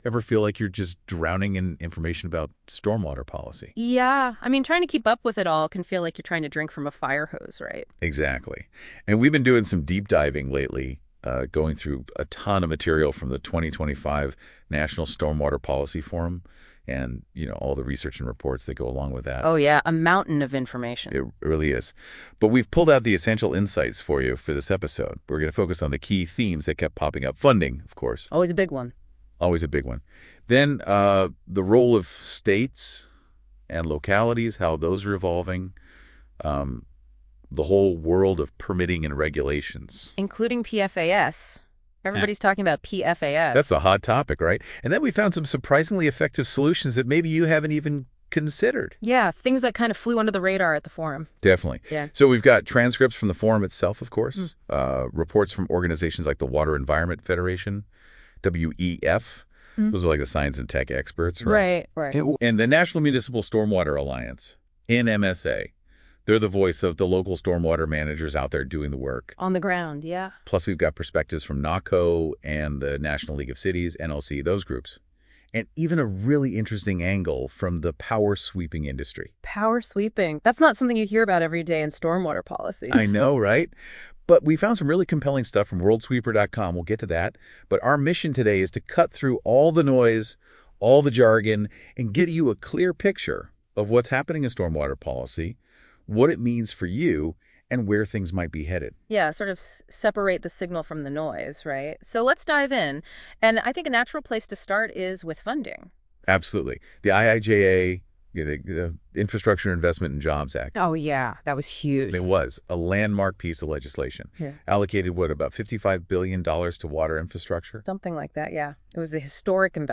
Listen to the captivating 23-minute, AI-generated, audio podcast that summarizes the information from the 4-hour event in mp3 format.